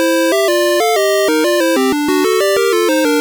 Source Recorded from the Sharp X1 version.